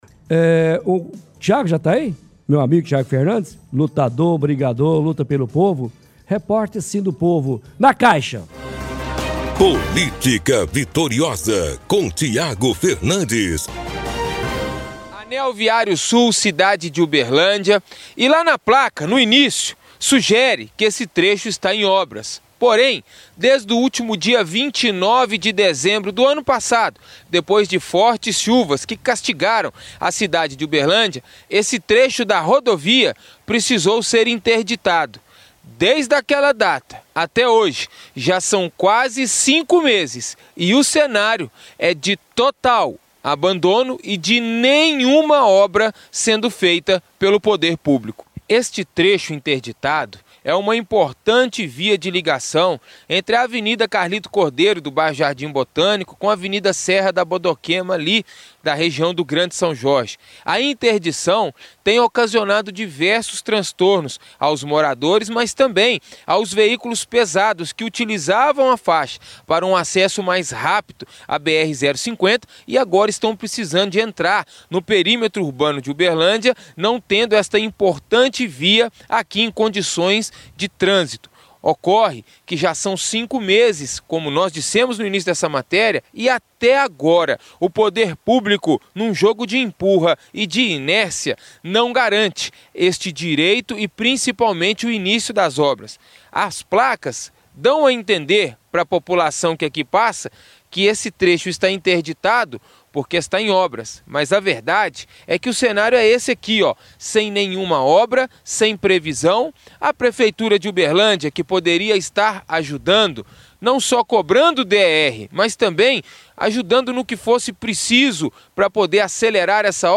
– Transmissão de reportagem de hoje do Chumbo Grosso.